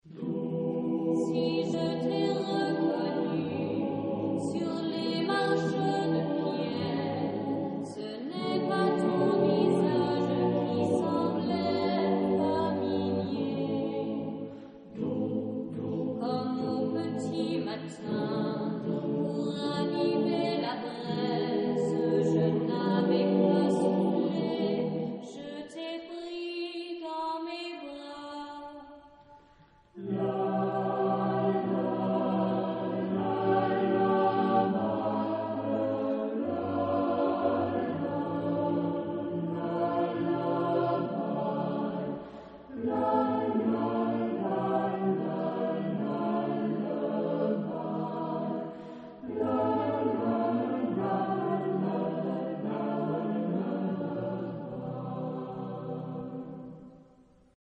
Genre-Style-Form: Contemporary ; Secular ; Partsong
Type of Choir: SATB  (4 mixed voices )
Soloist(s): Altos (4)  (4 soloist(s))
Instruments: Guitar (ad lib)
Tonality: C major ; E minor